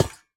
step5.ogg